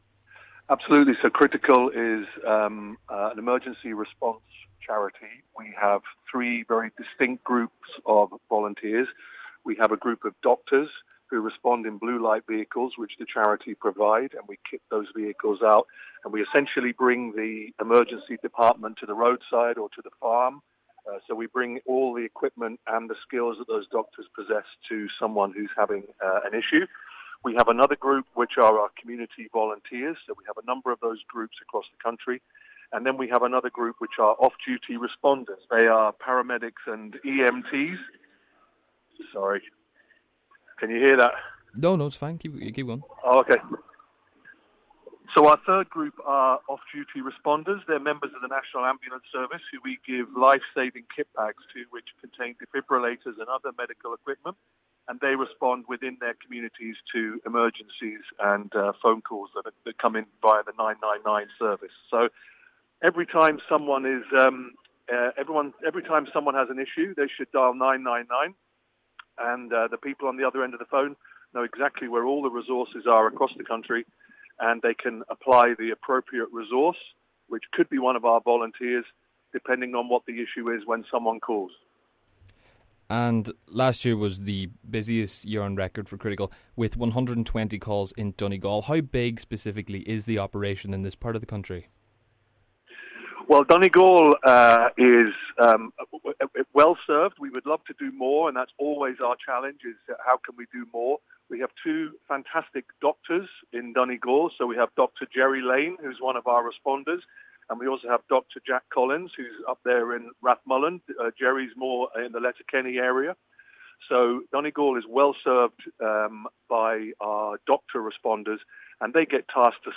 (Full Interview)